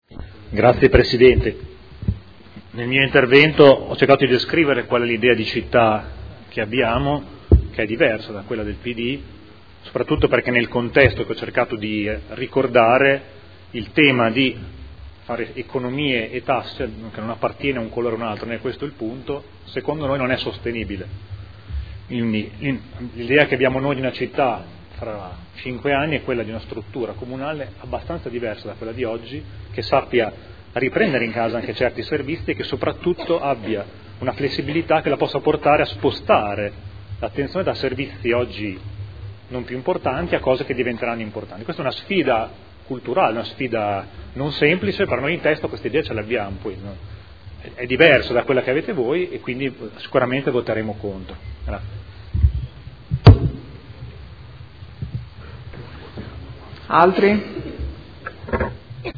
Seduta del 29/01/2015. Documento Unico di Programmazione 2015/2019 – Sezione strategica. Dichiarazione di voto